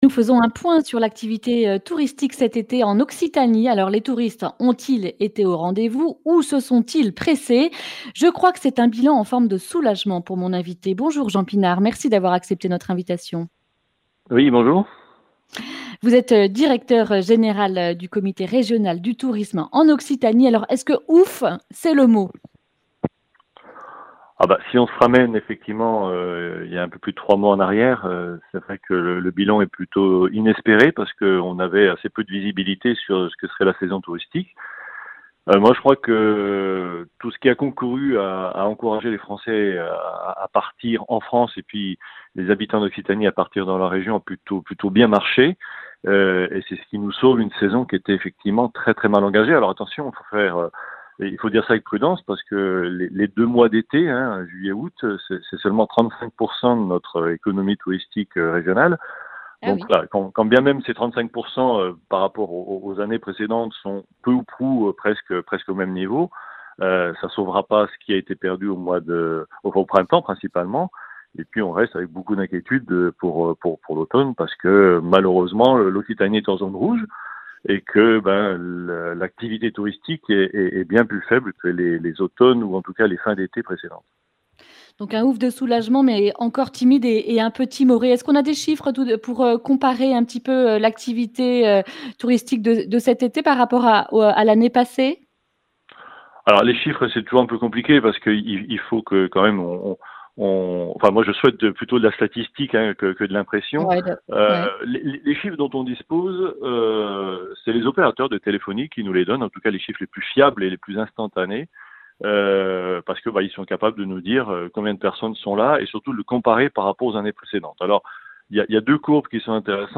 Accueil \ Emissions \ Information \ Régionale \ Le grand entretien \ Tourisme en Occitanie : une saison sauvée, des perspectives de croissance (...)